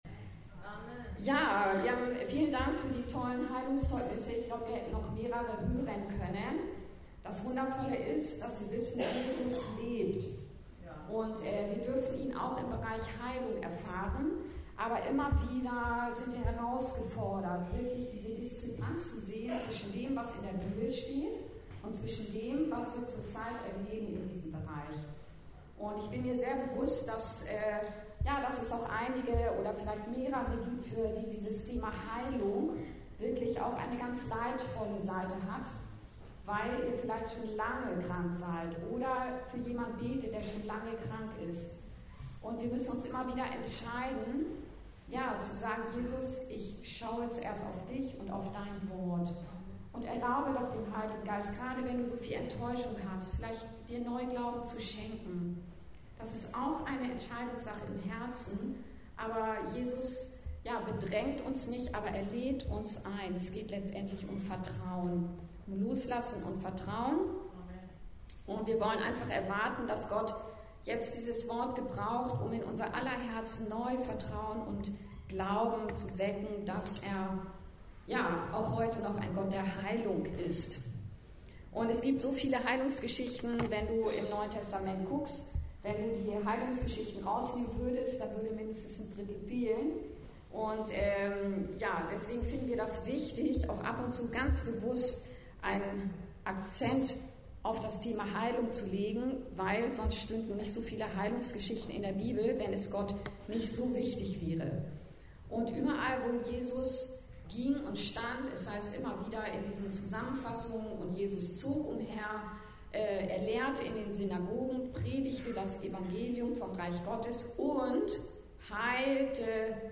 Jesus vergibt und heilt auch heute! (Lk.5,17-26) ~ Anskar-Kirche Hamburg- Predigten Podcast